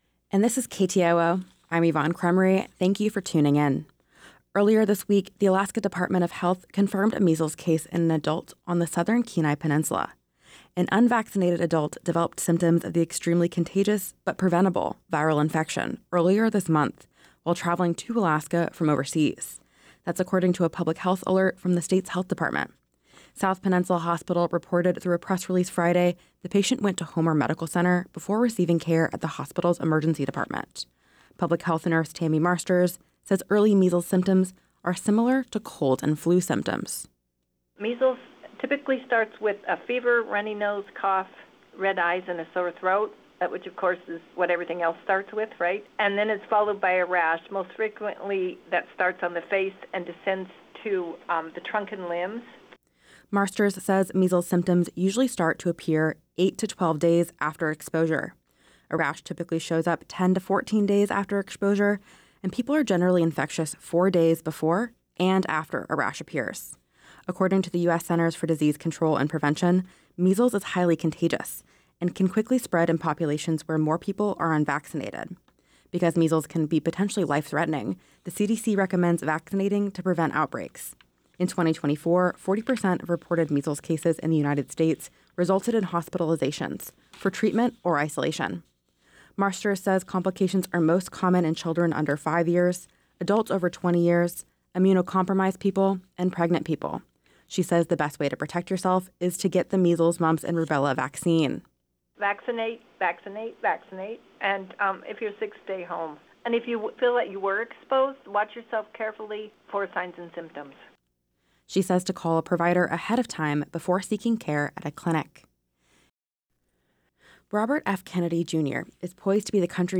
Newscast – Friday, Jan. 17, 2025